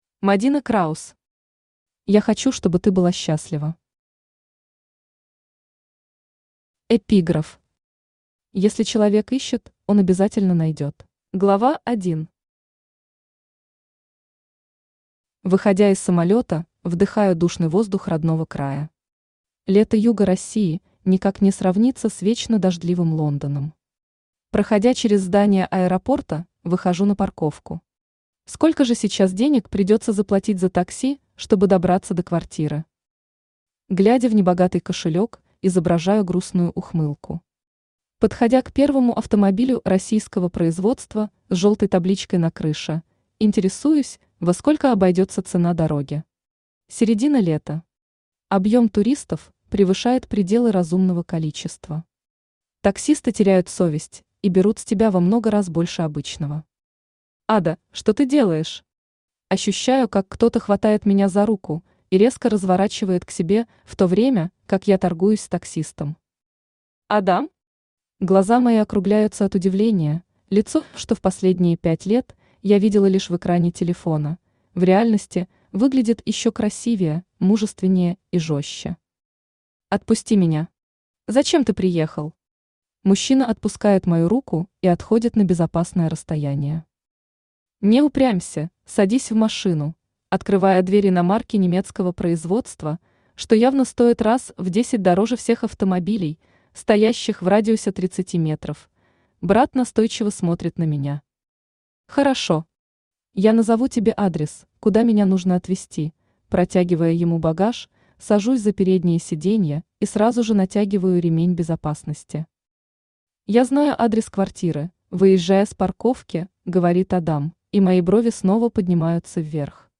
Aудиокнига Я хочу, чтобы ты была счастлива Автор Мадина Краус Читает аудиокнигу Авточтец ЛитРес.